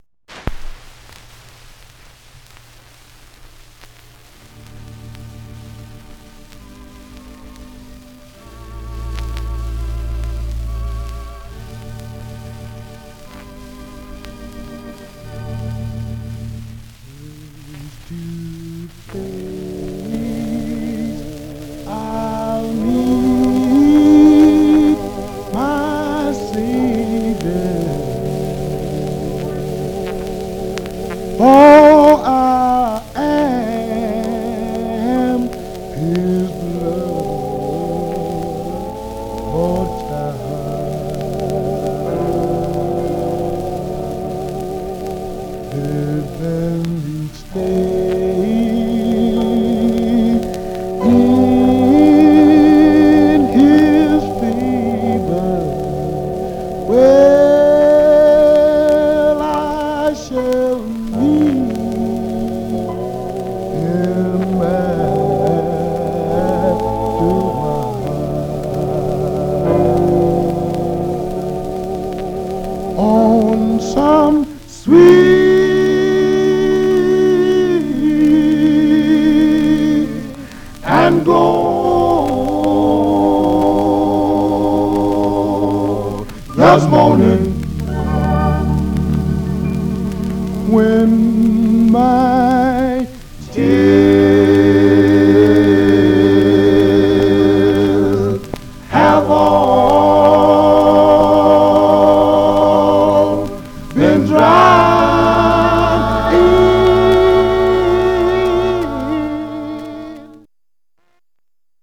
Some surface noise/wear Stereo/mono Mono
Male Black Group Condition